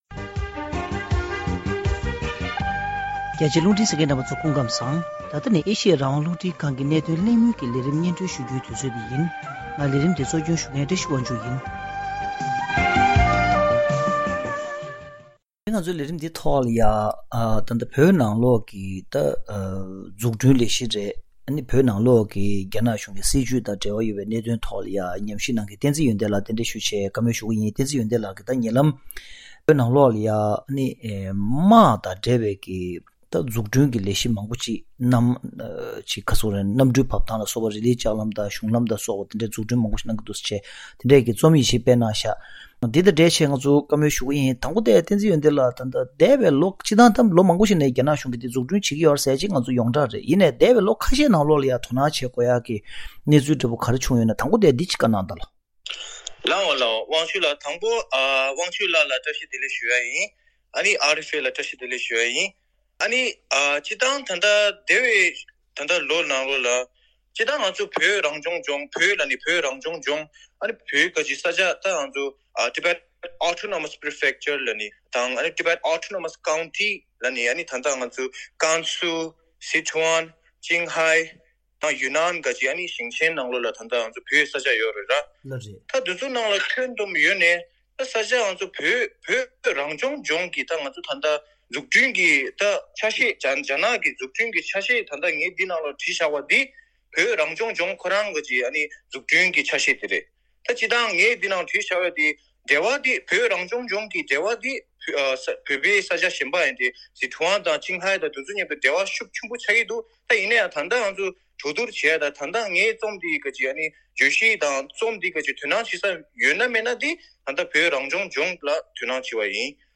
རྒྱ་ནག་གཞུང་གིས་བོད་ནང་དུ་དམག་དོན་གྱི་འཛུགས་སྐྲུན་རྒྱ་སྐྱེད་གཏོང་བཞིན་ཡོད་ཅིང་། ཕྱི་ལོ་ ༢༠༣༥ བར་བོད་ནང་དུ་གནམ་གྲུ་བབ་ཐང་དང་། རི་ལི་ལྕགས་ལམ། མོ་ཊའི་གཞུང་ལམ་བཅས་རྒྱ་སྐྱེད་གཏོང་རྒྱུའི་ལས་དོན་ཚགས་ཚུད་དུ་གཏོང་དགོས་པའི་སྐུལ་འདེད་བྱེད་ཀྱི་ཡོད་པ་རེད། ཐེངས་འདིའི་གནད་དོན་གླེང་མོལ་གྱི་ལས་རིམ་ནང་།